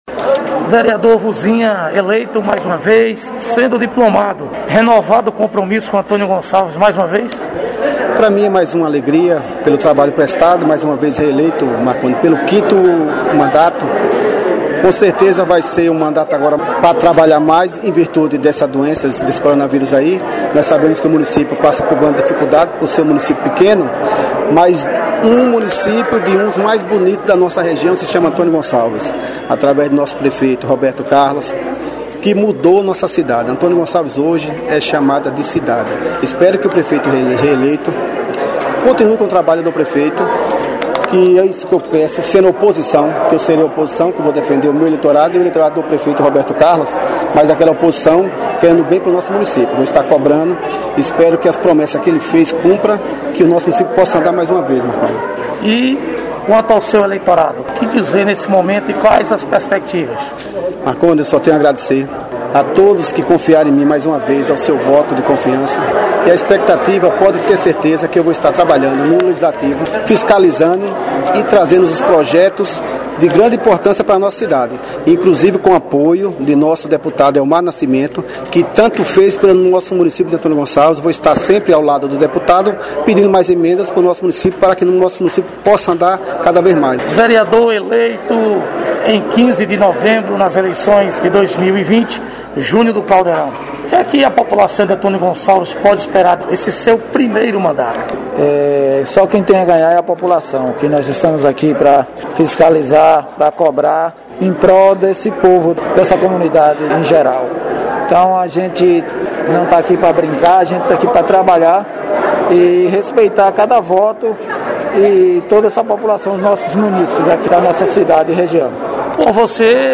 Entrevista: vereadores diplomados em Antônio Gonçalves-BA